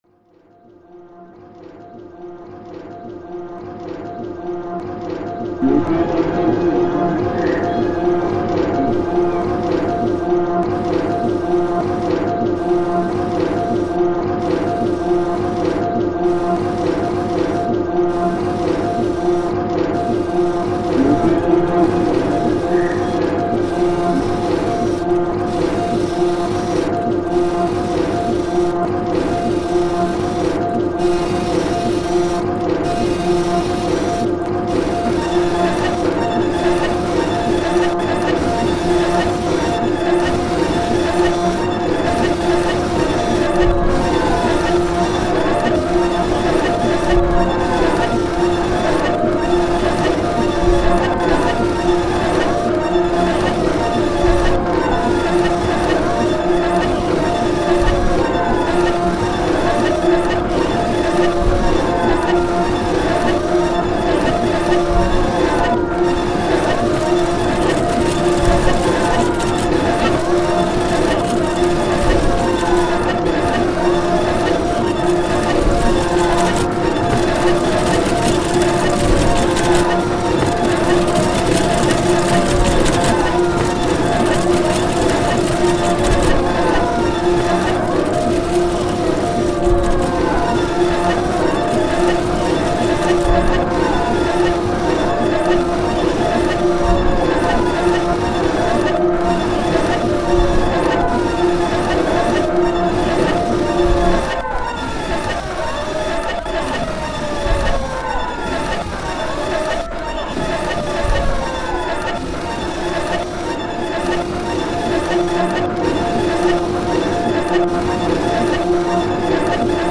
Leur présence s'est accompagnée de l'enregistrement in situ du son.